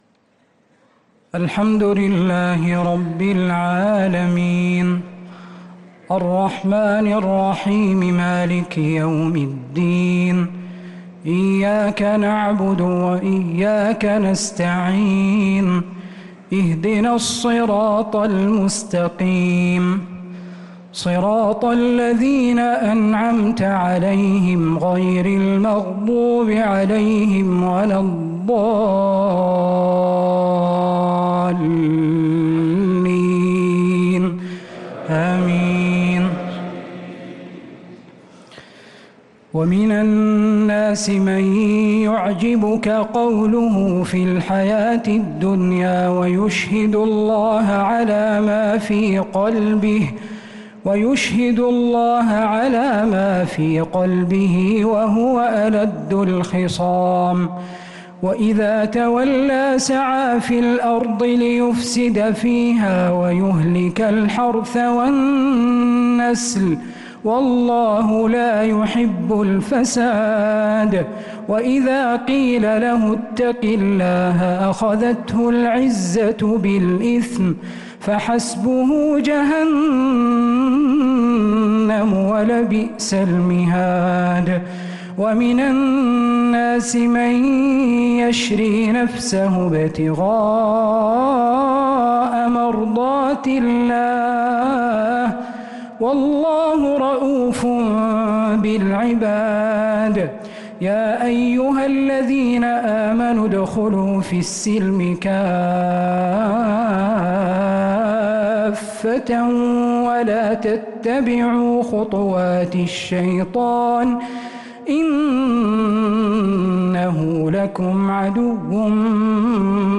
صلاة التراويح ليلة 3 رمضان 1445
الثلاث التسليمات الأولى صلاة التراويح